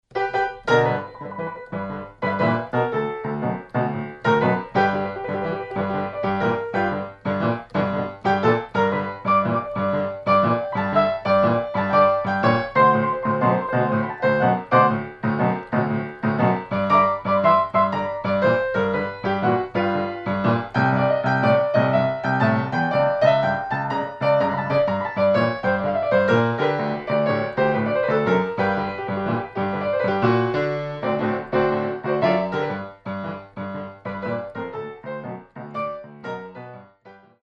Style: Jump Blues Piano